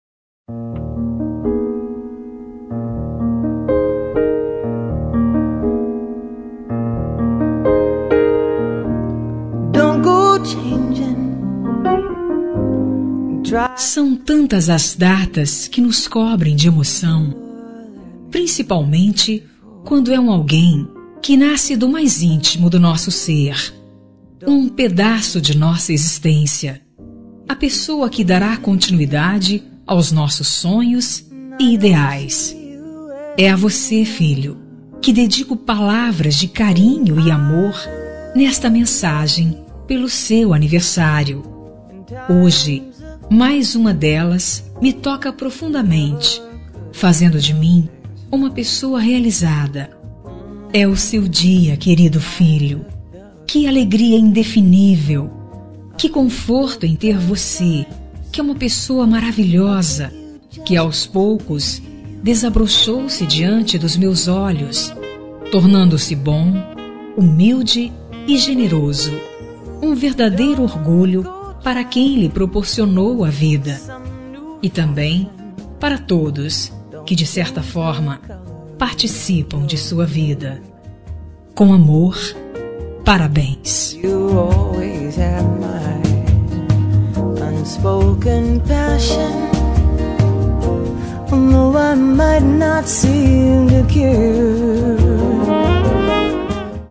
Aniversário de Filho – Voz Feminina – Cód: 5302